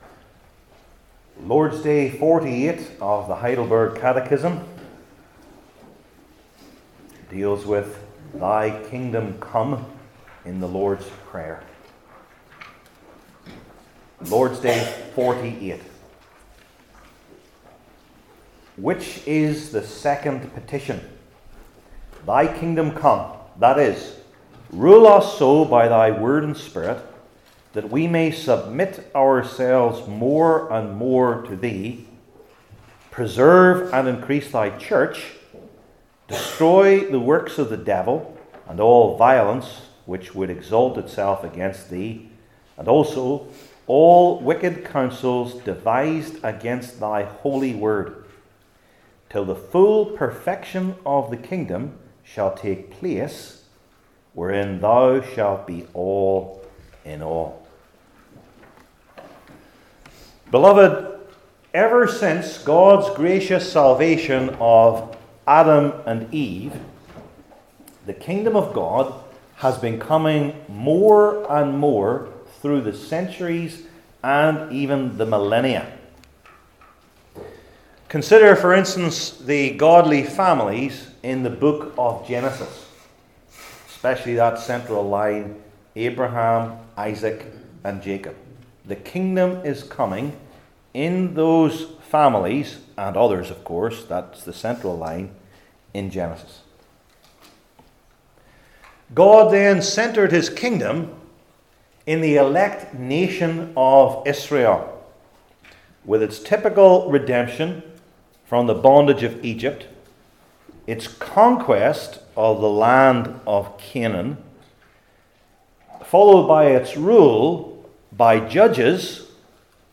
Heidelberg Catechism Sermons I. Praying for God’s Rule II.